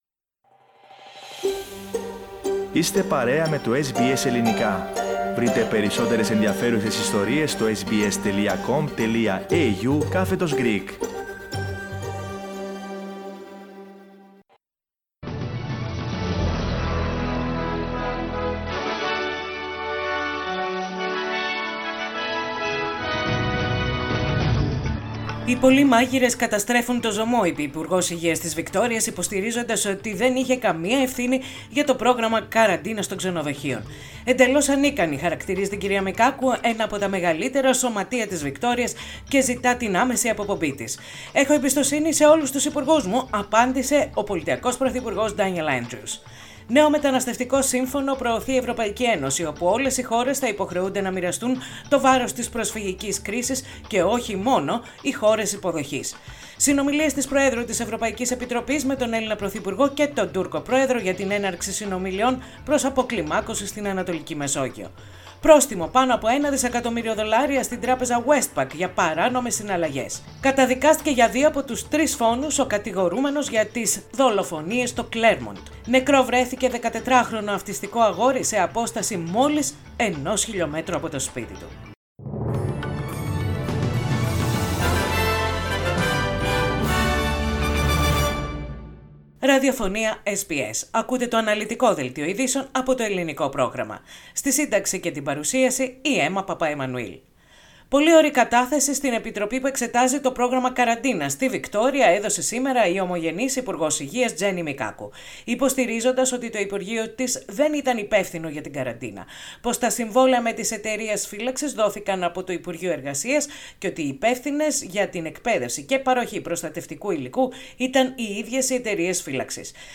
Δελτίο ειδήσεων - Πέμπτη 24.9.20
Οι κυριότερες ειδήσεις της ημέρας από το Ελληνικό πρόγραμμα της ραδιοφωνίας SBS.